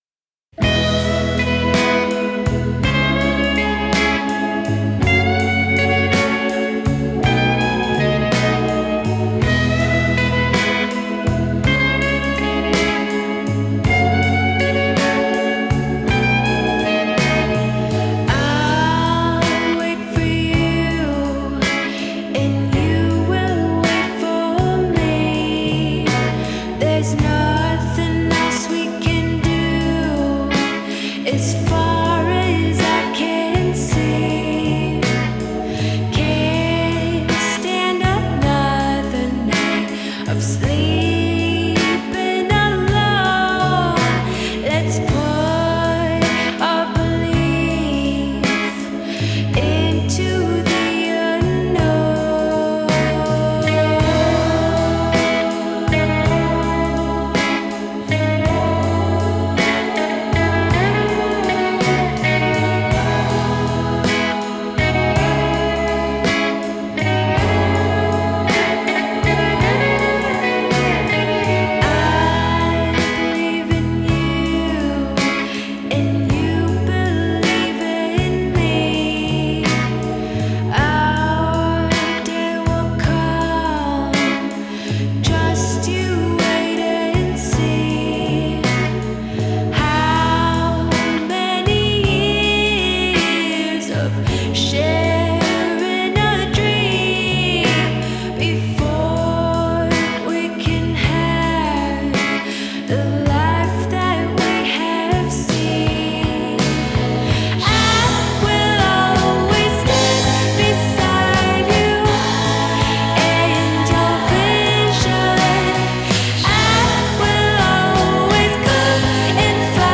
Genre: indie pop, dream pop, indie rock